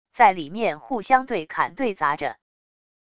在里面互相对砍对砸着wav音频生成系统WAV Audio Player